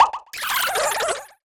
fadeout.wav